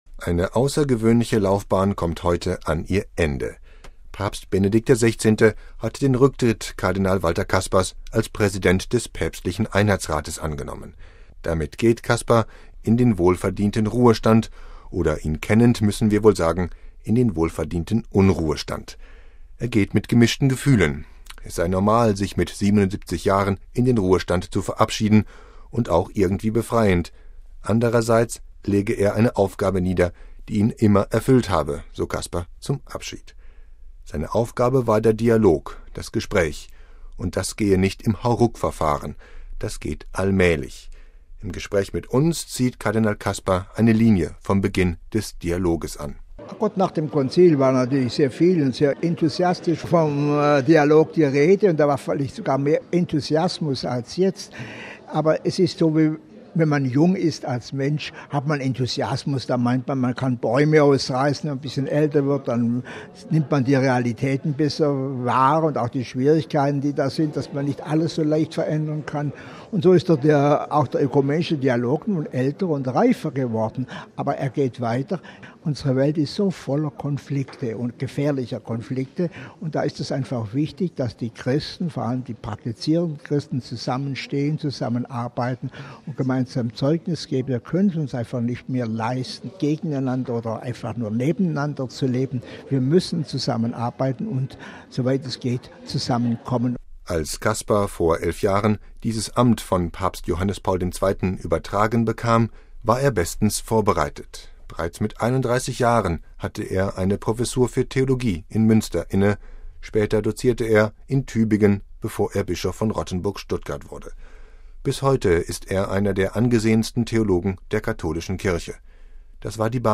Im Gespräch mit uns zieht Kardinal Kasper eine Linie vom Beginn des Dialoges an: